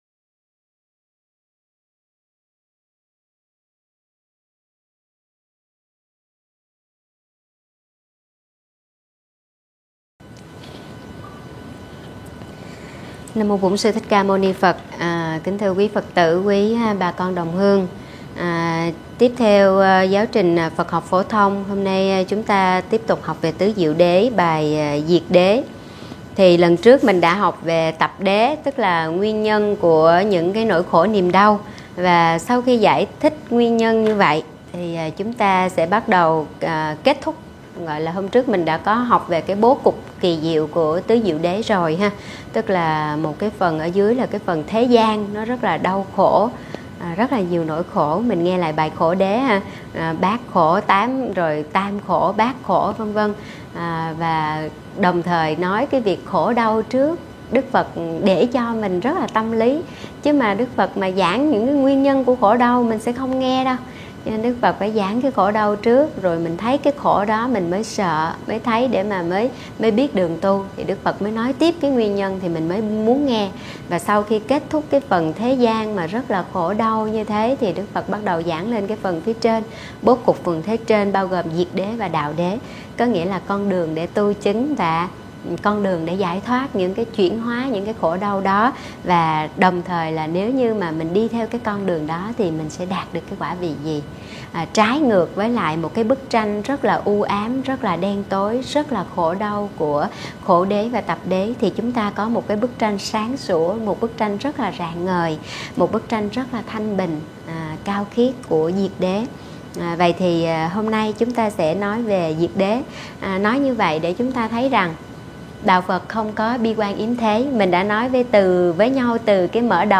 Thuyết pháp Diệt đế: Hạnh phúc tối thượng